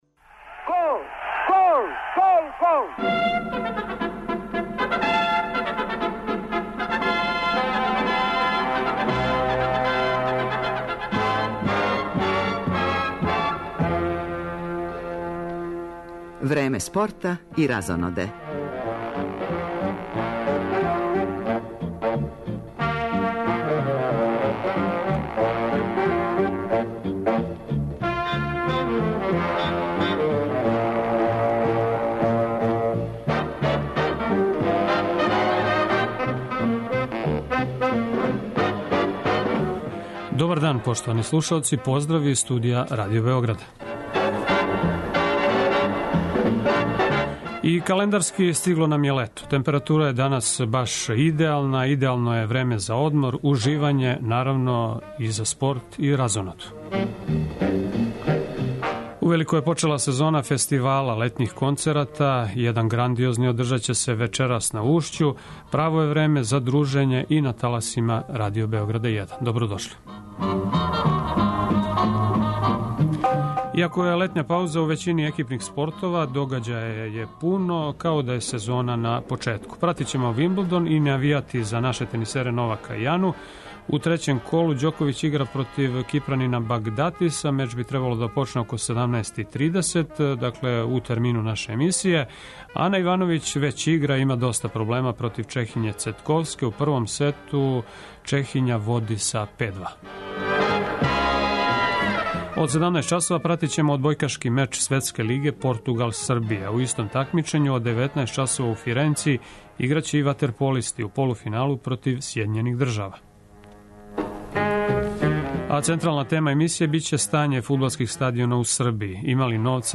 Из Ужица и Ниша јављају нам се репортери од којих ћемо чути докле се стигло са радовима на изградњи стадиона Чаир и Слобода.